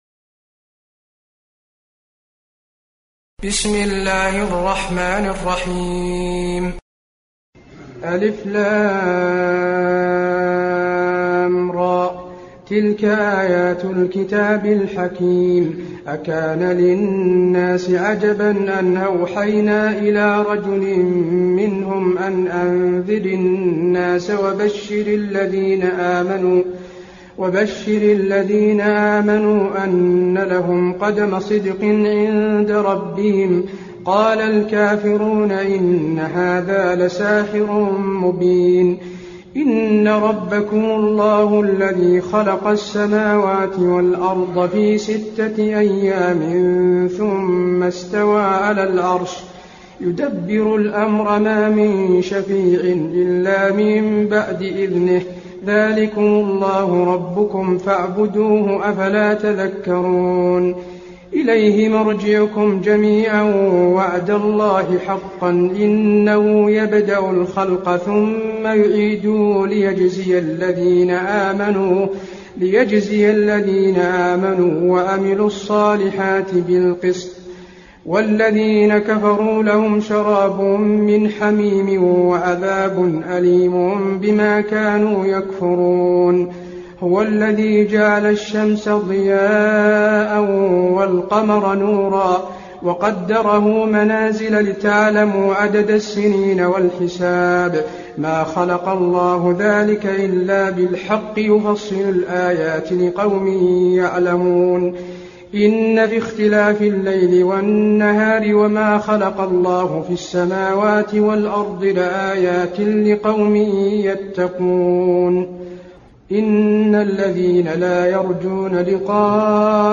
المكان: المسجد النبوي يونس